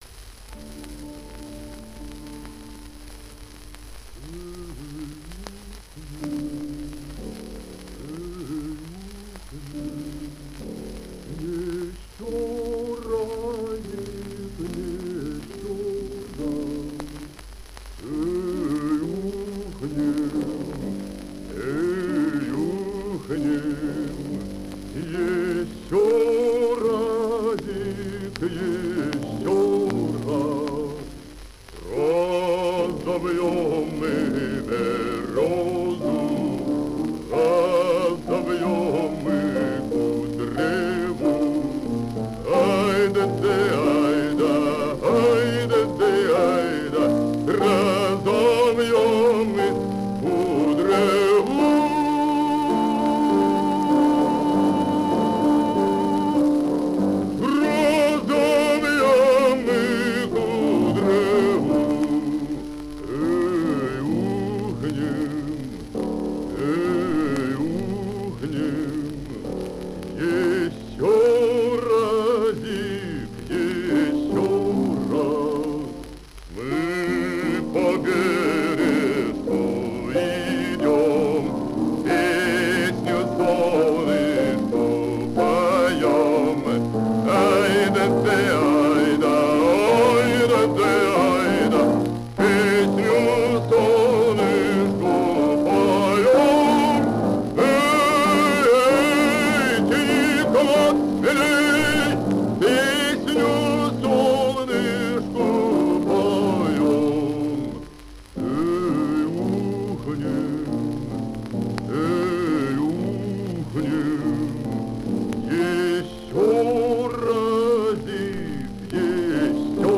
Русская народная песня «Эй, ухнем!».
Исполняет В. И. Касторский. Партия фортепиано